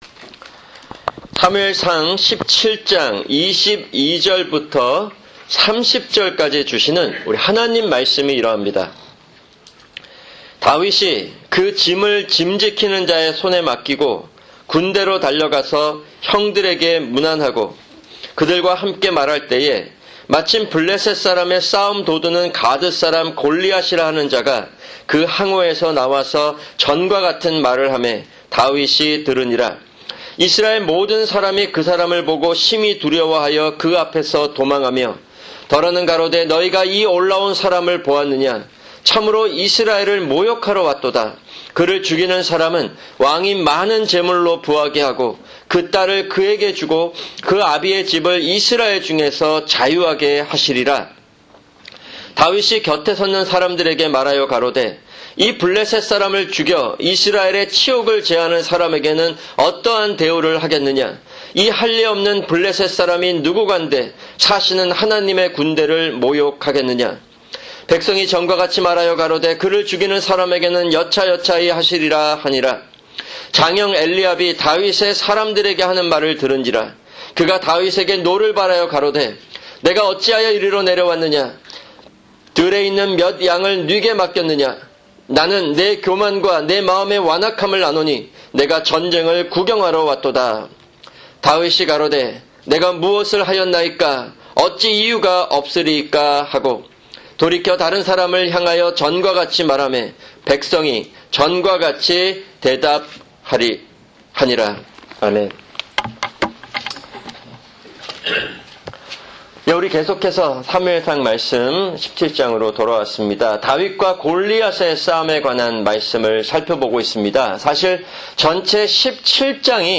[주일 설교] 사무엘상(49) 17:22-30